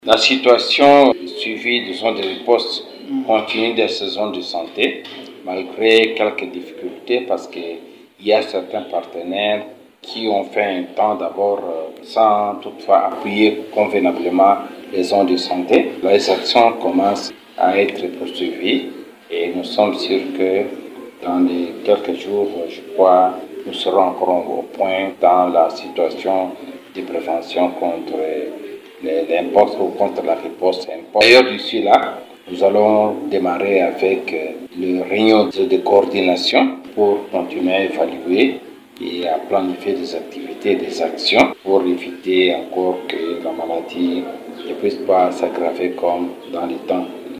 dans un entretien avec Radio Maendeleo.